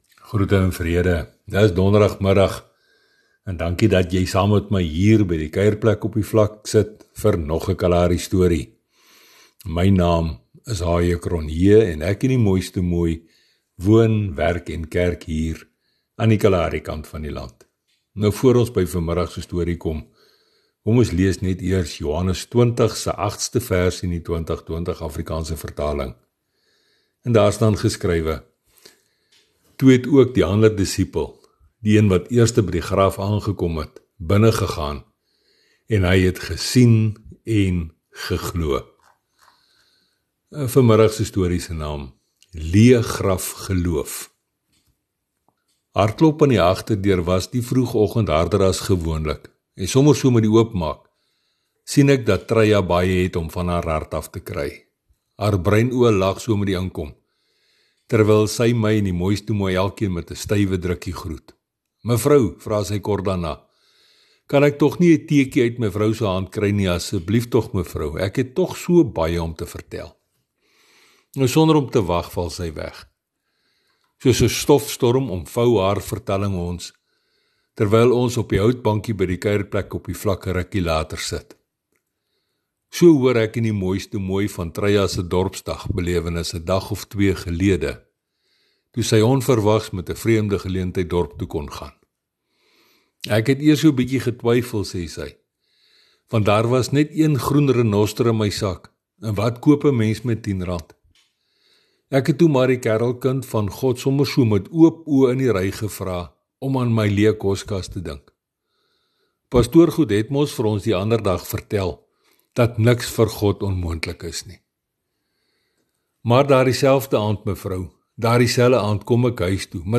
Sy getuienis verhale het 'n geestelike boodskap, maar word vertel in daardie unieke styl wat mens slegs daar op die kaal vlaktes kan optel.